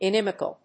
in・im・i・cal /ɪnímɪk(ə)l/
• / ɪnímɪk(ə)l(米国英語)